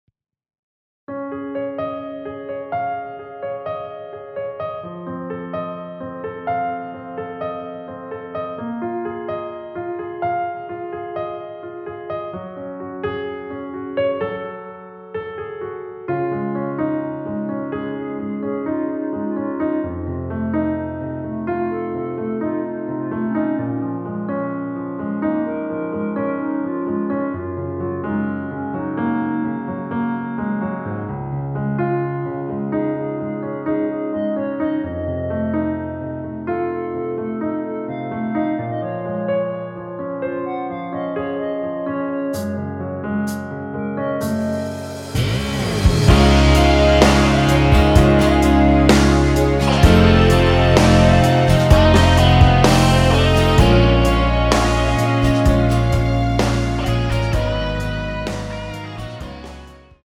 원키에서(-1)내린 (1절앞+후렴)으로 진행되게 편곡한 멜로디 포함된 MR입니다.
Db
앞부분30초, 뒷부분30초씩 편집해서 올려 드리고 있습니다.